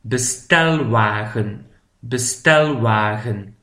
PRONONCIATION